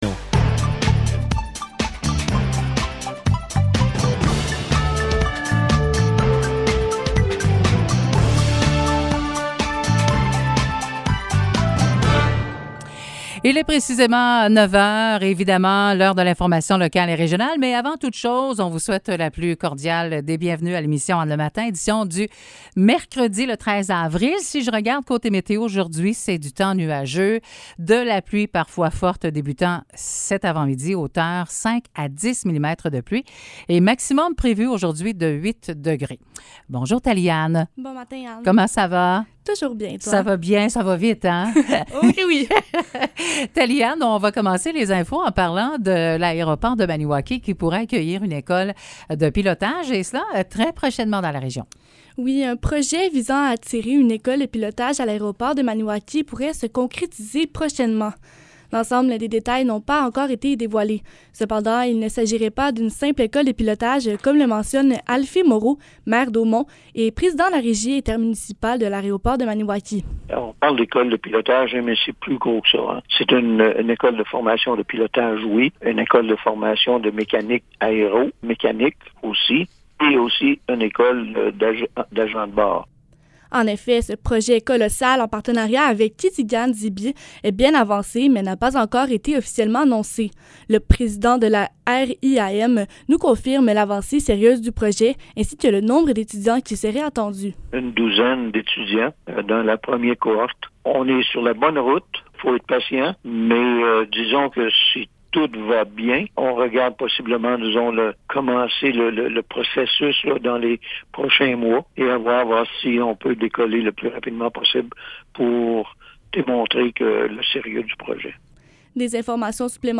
Nouvelles locales - 13 avril 2022 - 9 h